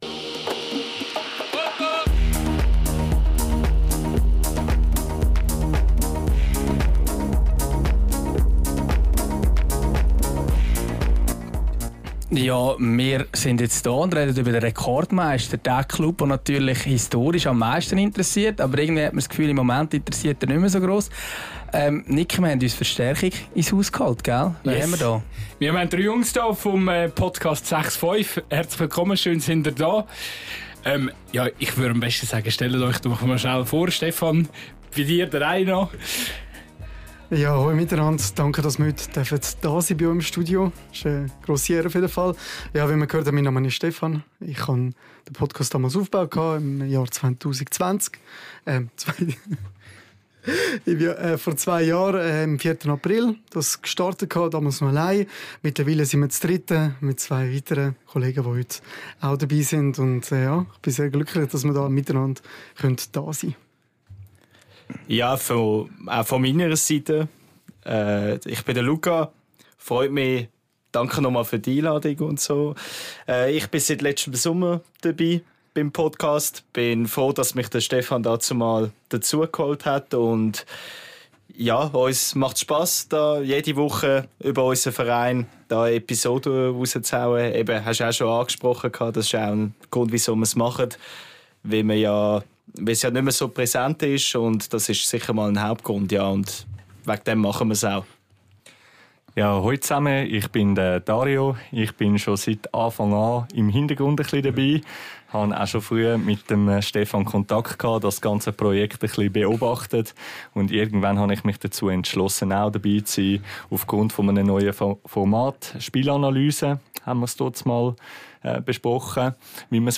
Drei Jungs vom Podcast «Sechsfoif» versuchen, Licht ins Dunkel zu bringen – und haben sich ins Zweikampf-Aufnahmestudio dazugesellt.